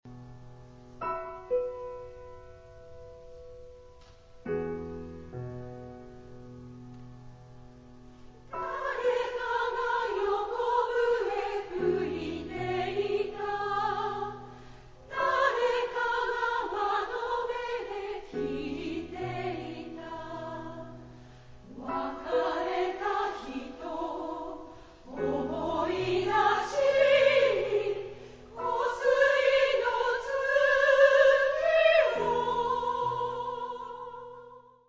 ライブ録音ＣＤ-R
中級用女声３部合唱曲集で，構成はソプラノ，メゾ･ソプラノ，アルトです。
歌いやすいメロディーとハーモニーで，親しみやすい女声合唱曲集に仕上がっています。